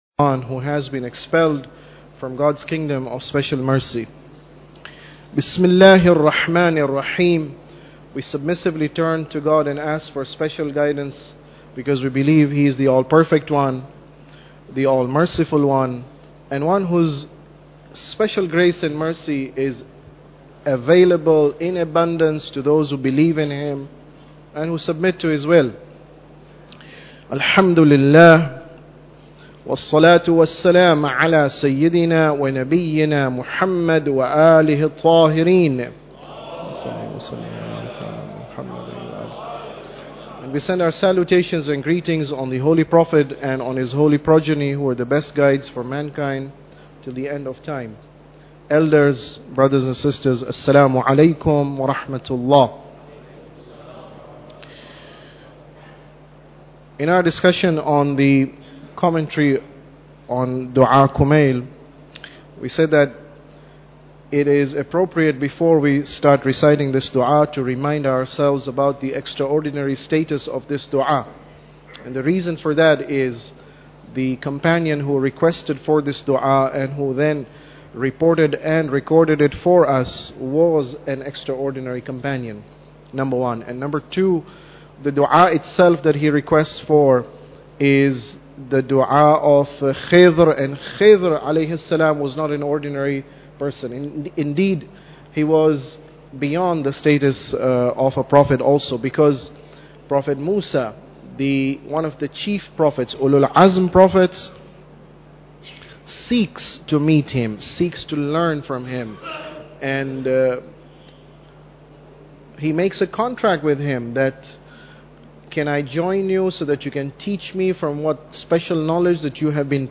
Tafsir Dua Kumail Lecture 10